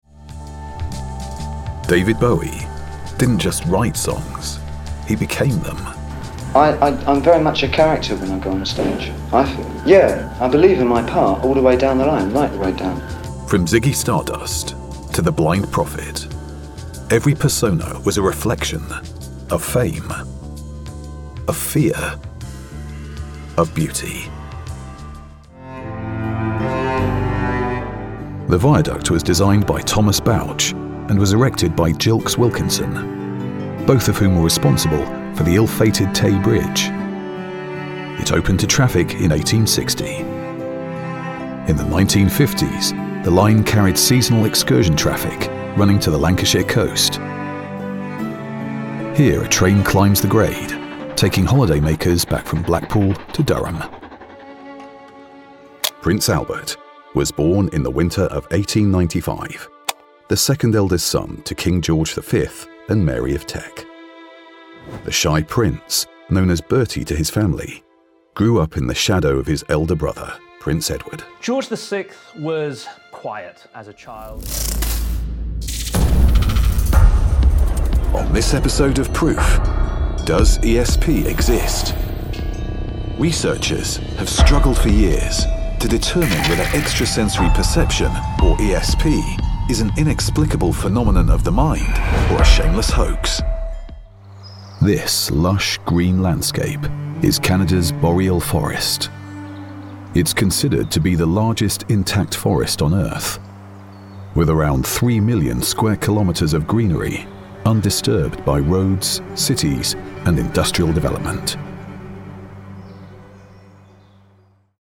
Voice Over Narration, Talent Artists & Actors
Adult (30-50) | Older Sound (50+)